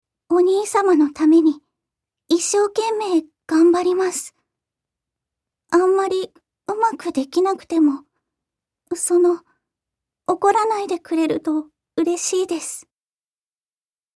自己紹介
セリフ1